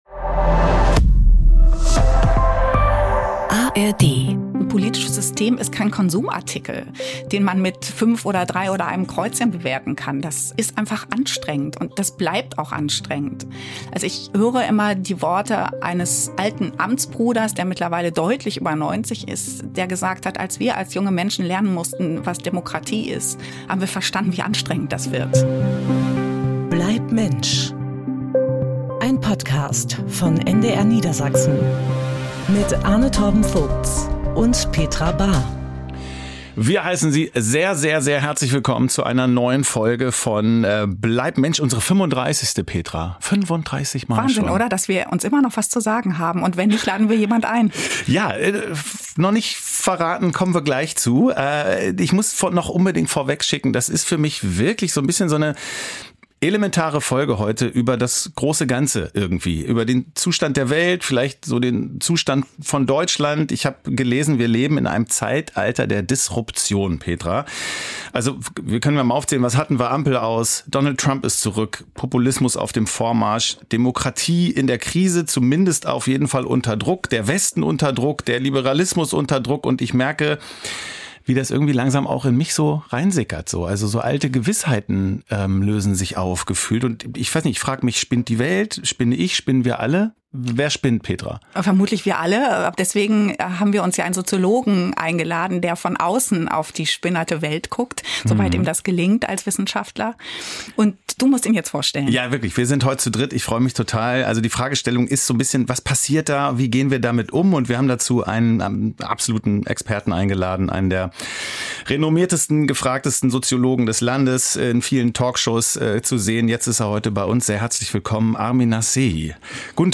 Über die Unzufriedenheit der Gesellschaft und den Vertrauensverlust in die Politik sprechen Ethikerin Petra Bahr